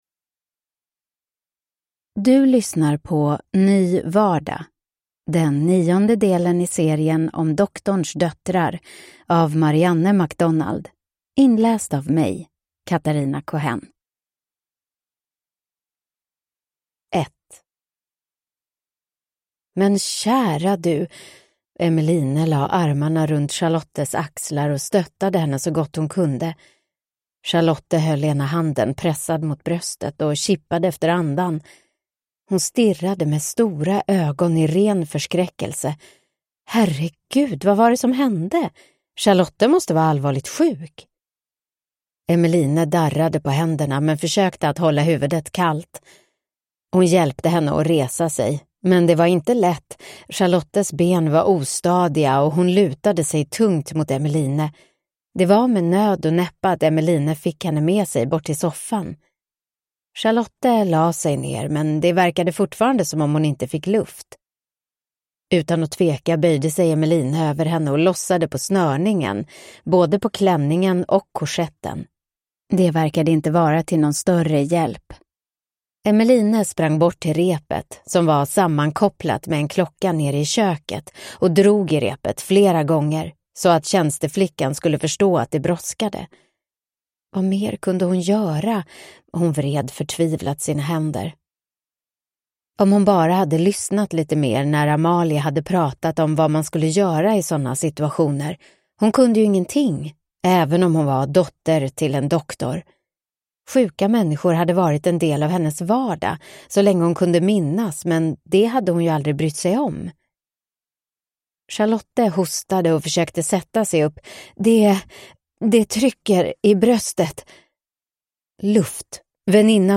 Ny vardag (ljudbok) av Marianne MacDonald